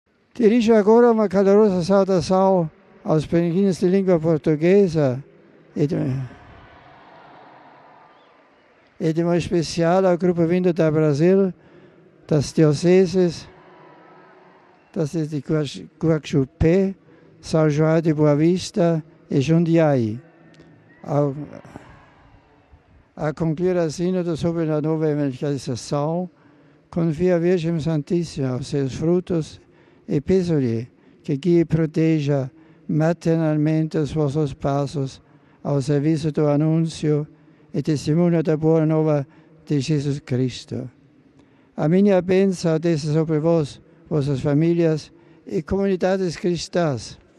Mas voltemos às palavra do Papa no ângelus com a saudação em